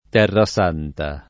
tHrraS#nta] o Terra Santa [id.] top. f. — pl., occorrendo, Terresante [tHrreS#nte]: non c’erano più Terresante da conquistare [non © $rano pL2 ttHrreS#nte da kkojkUiSt#re] (Cardarelli)